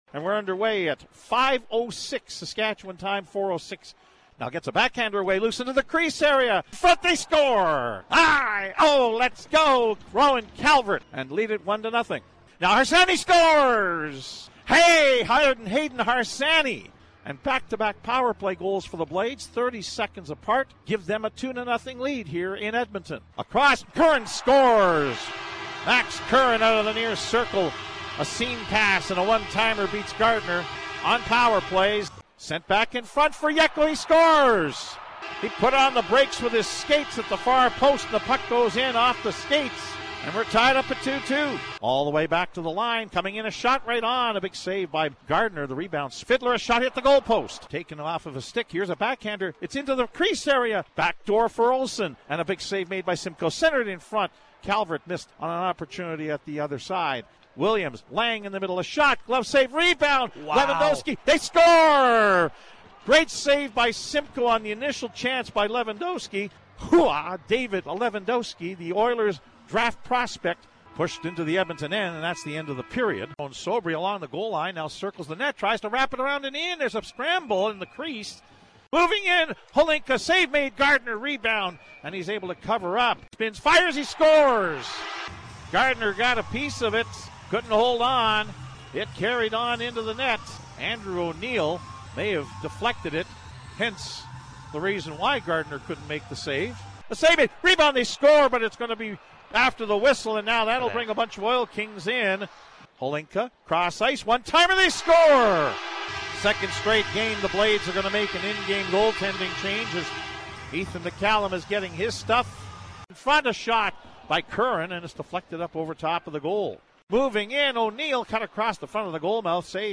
Following are the audio highlights from CJWW’s broadcast